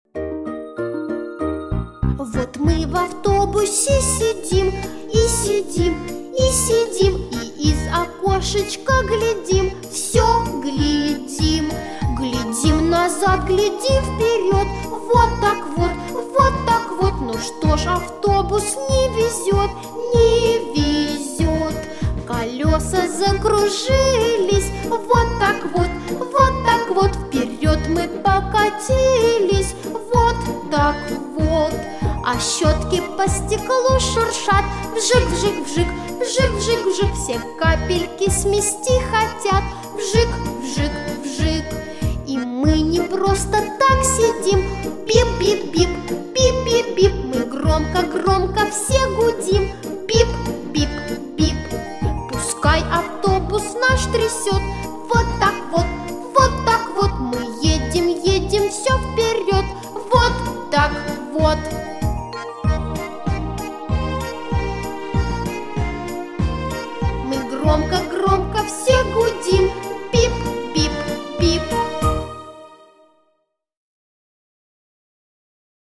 Детская музыка.
Весёлая музыка для детей.